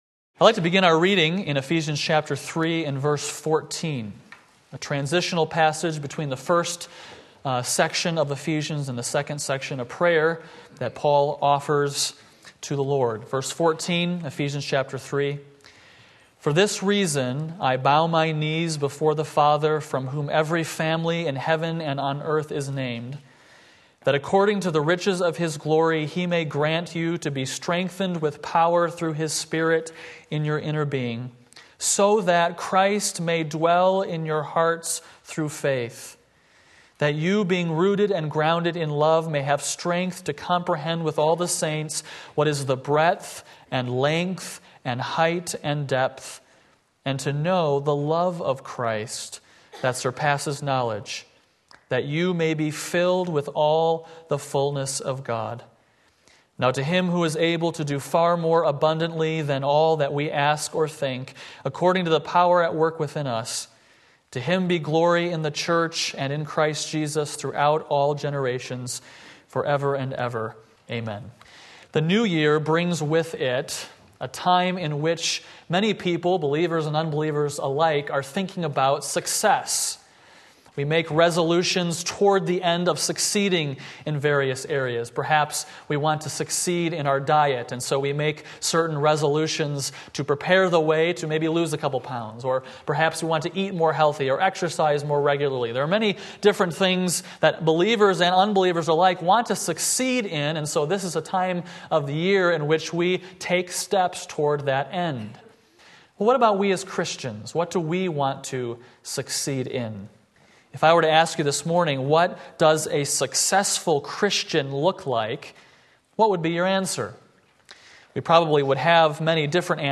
Sermon Link
The Missing Link Between Head and Hands Ephesians 3:14-21 Sunday Morning Service